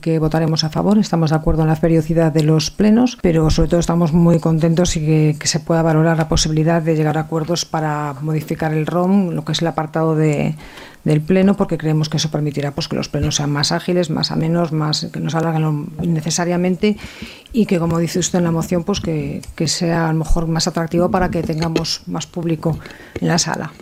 La regidora del Partit Popular, Celinie Coronil, ha expressat el seu suport a la moció destacant la importància de reformar el ROM per tal que les sessions siguin “més àgils i atractives per al públic”.